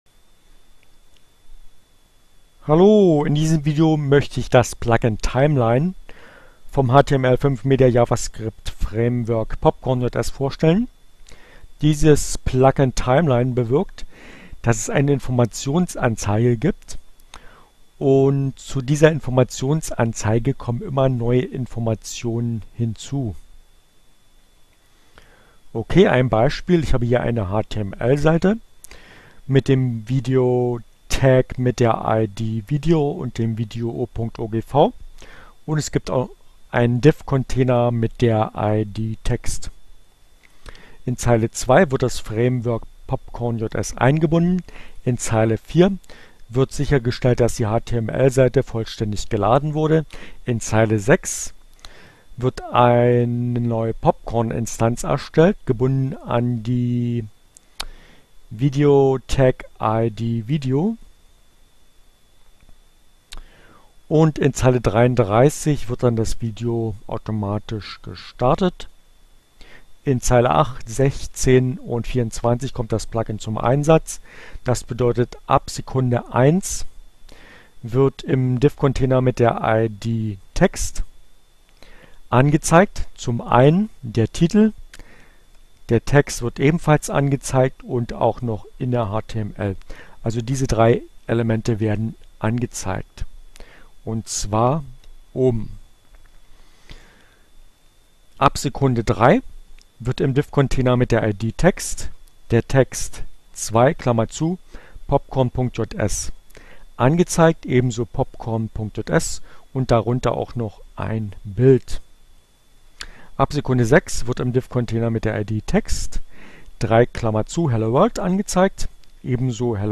Tags: Fedora, Gnome, Linux, Neueinsteiger, Ogg Theora, ohne Musik, screencast, CC by, Gnome3, HTML5, Javascript, Mozilla, popcornjs, timeline